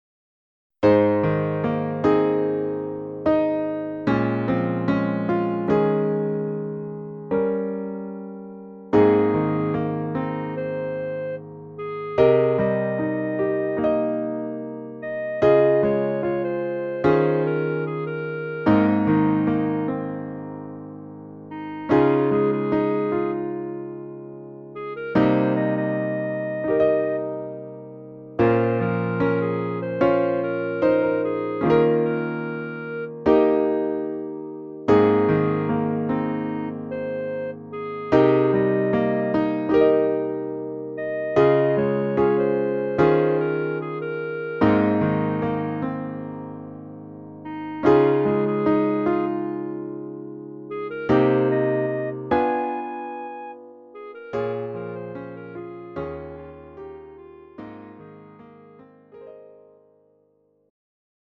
음정 -1키
장르 가요 구분 Lite MR
Lite MR은 저렴한 가격에 간단한 연습이나 취미용으로 활용할 수 있는 가벼운 반주입니다.